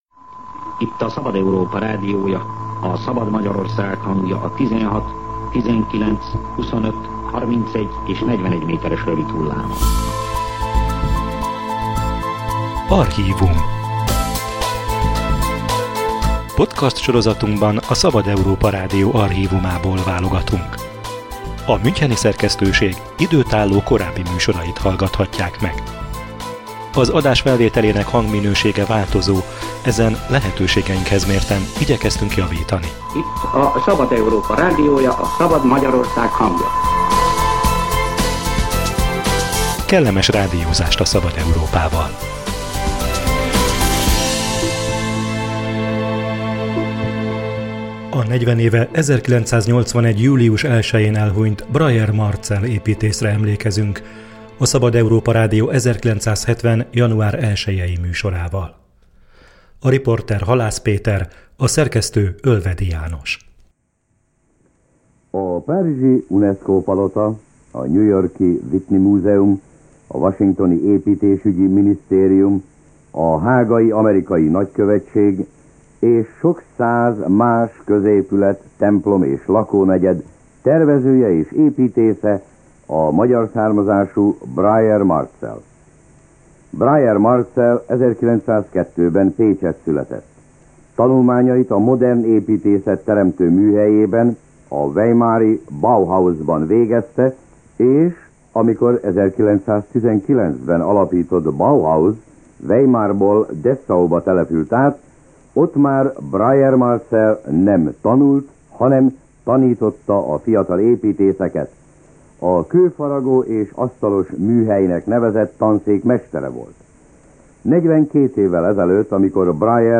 Nagyobb vonalakra és nagyobb dimenziókra van szükség – mondta a Szabad Európa Rádiónak 1979-ben Breuer Marcell, amikor a modern építészet jellegzetességeiről kérdezték.